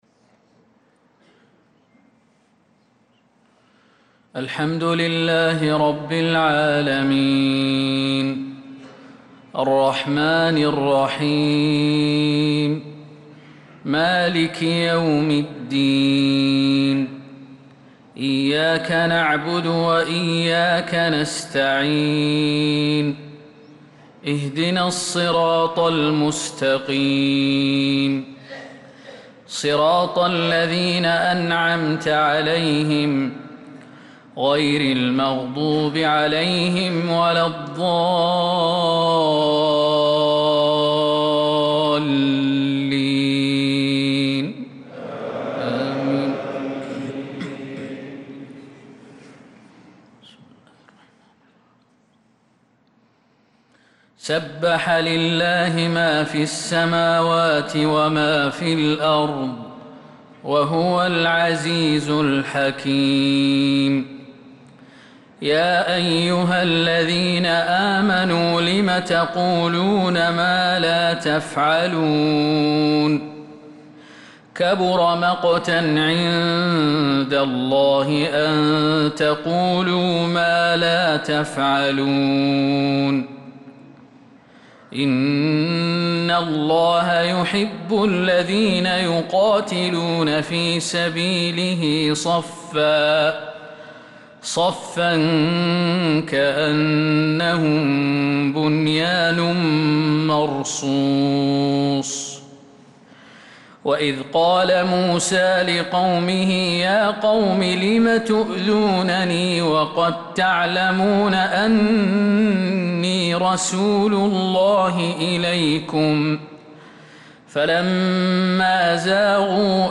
صلاة الفجر للقارئ خالد المهنا 12 شوال 1445 هـ
تِلَاوَات الْحَرَمَيْن .